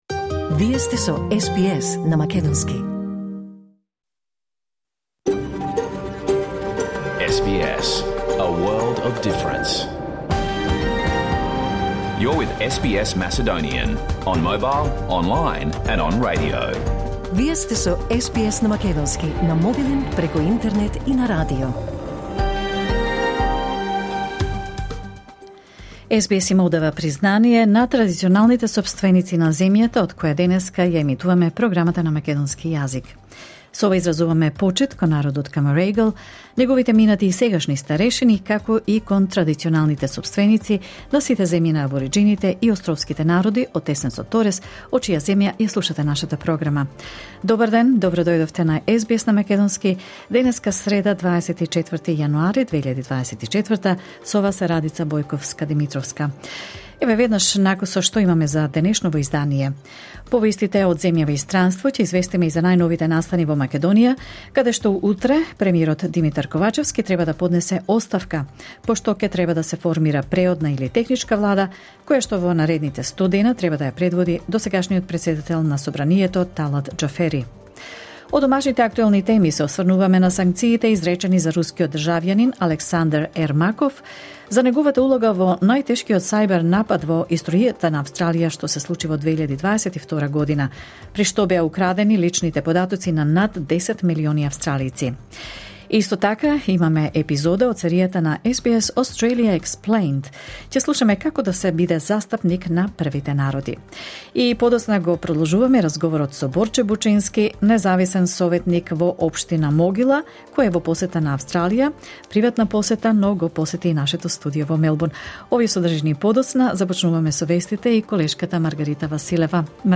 SBS Macedonian Program Live on Air 24 January 2024